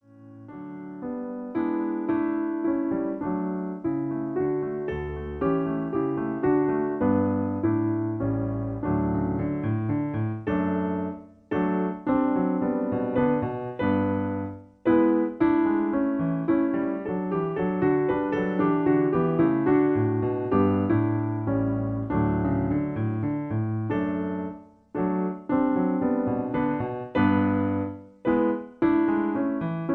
In D. Piano Accompaniment